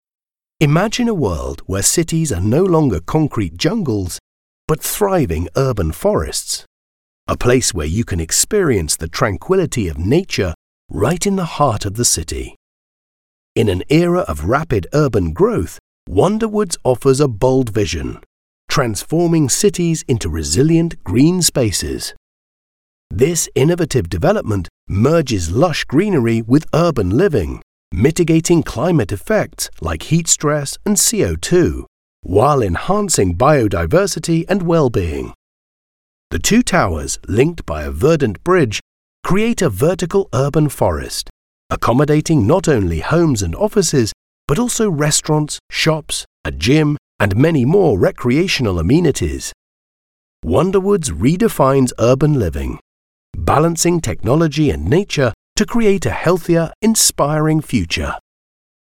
Anglais (Britannique)
Commerciale, Naturelle, Fiable, Chaude, Corporative
Vidéo explicative